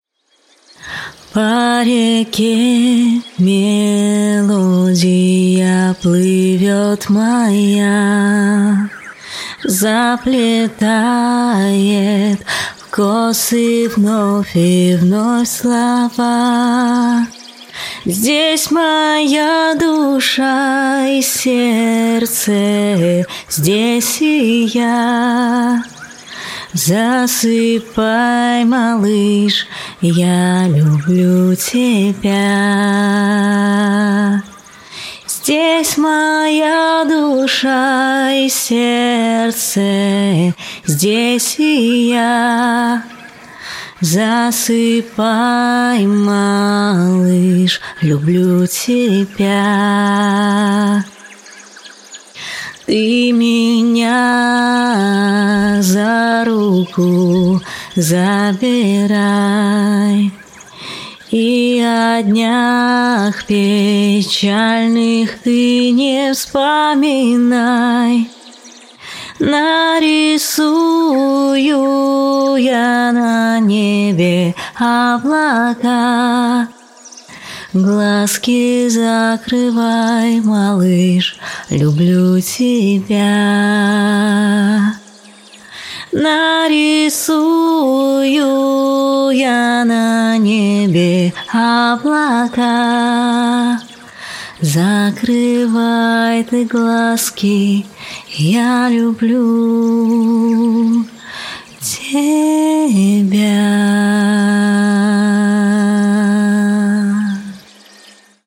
🎶 Детские песни / Колыбельные песни